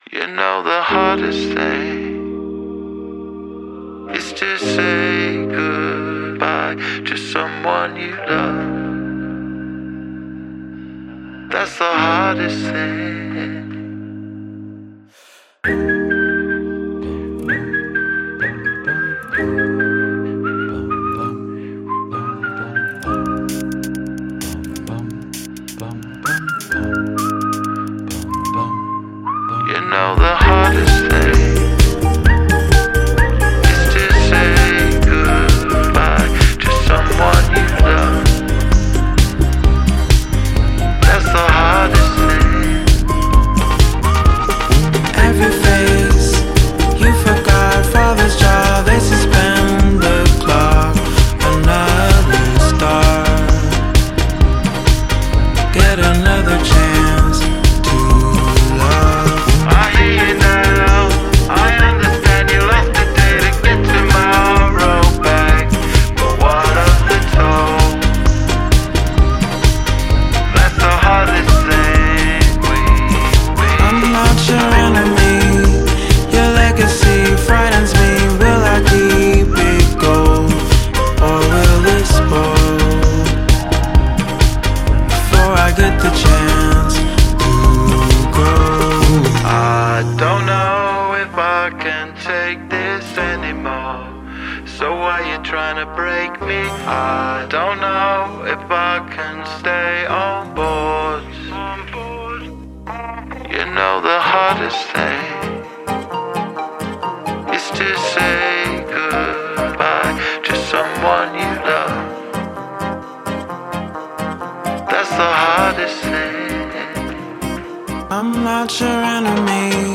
the track feels borderless.